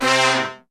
C3 POP FALL.wav